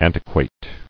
[an·ti·quate]